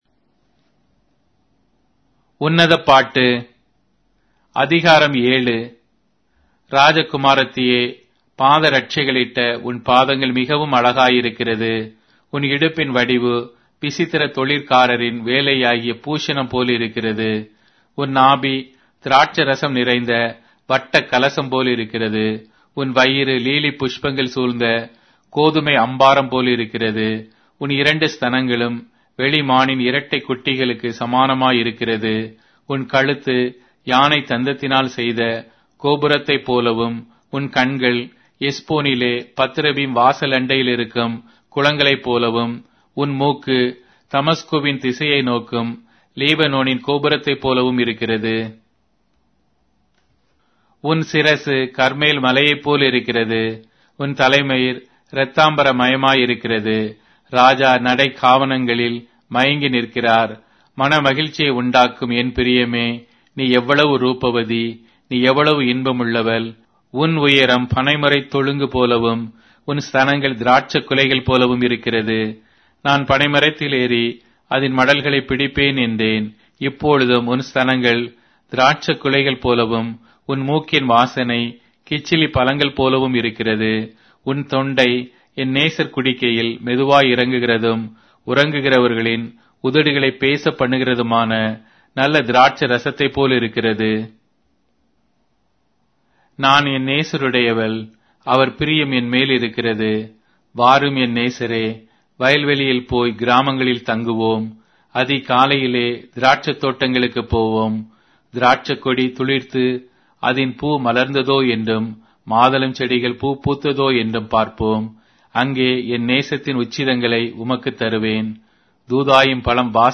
Tamil Audio Bible - Song-of-Solomon 4 in Asv bible version